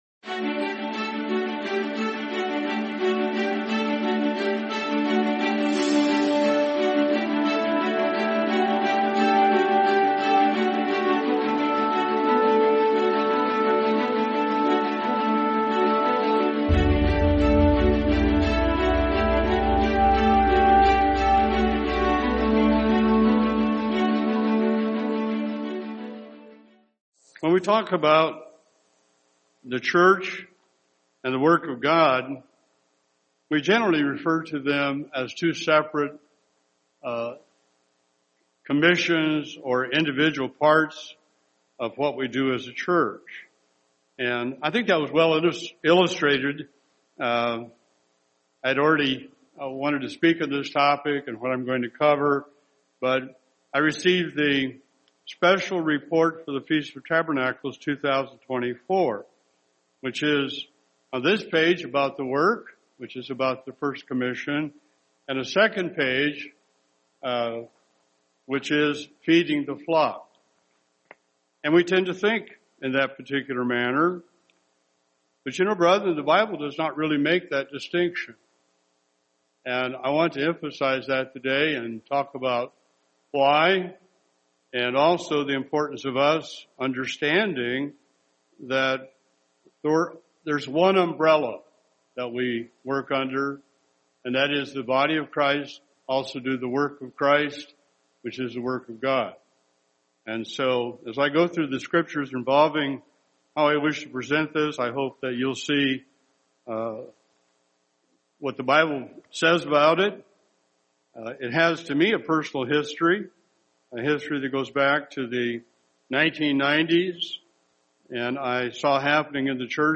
Sermon Feast of Tabernacles 2025: Bigger Than Ourselves